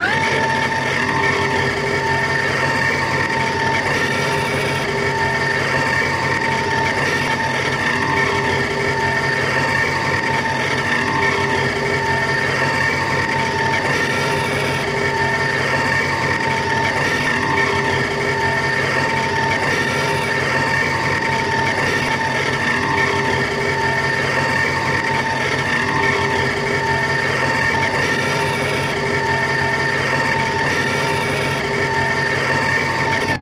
Radar system being rotated.